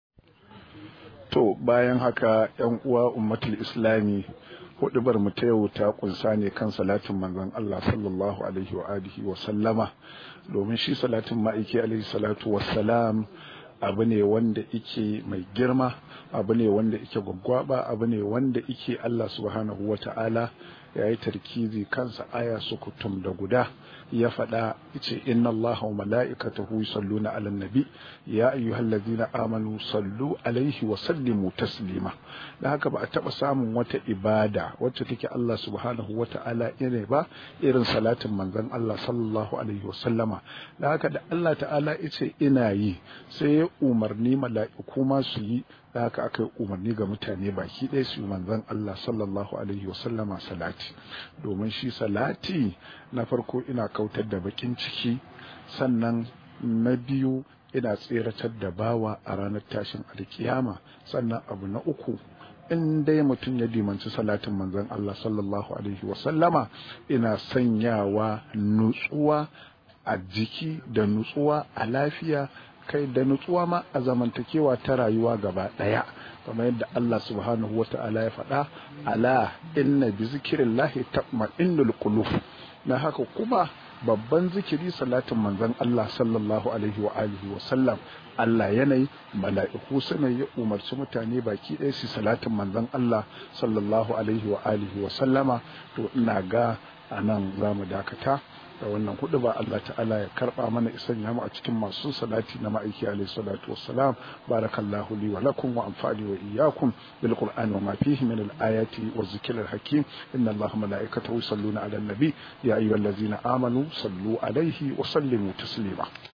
Rahoto: Salatin Annabi ya na ya ye bakin cikin zuciya – Limami